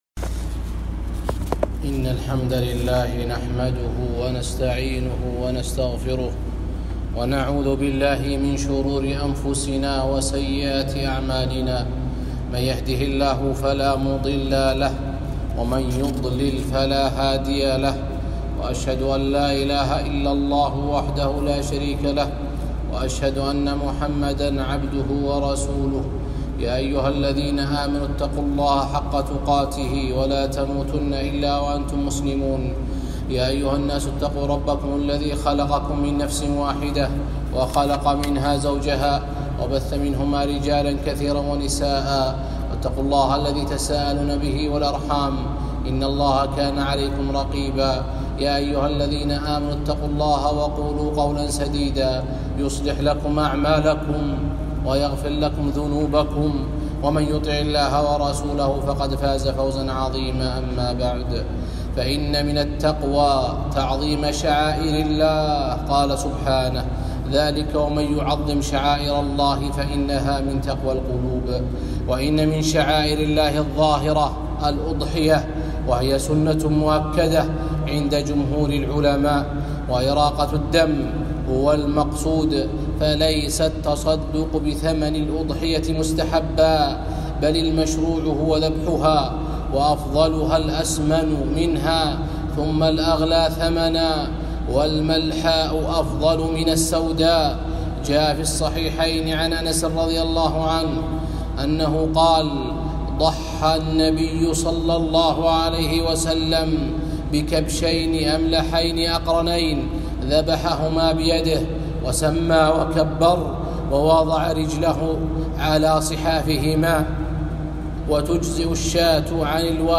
خطبة - الأضحية من شعائر الله 3 ذو الحجة 1441هــ